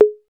Index of /90_sSampleCDs/Roland - Rhythm Section/DRM_Drum Machine/DRM_Cheese menus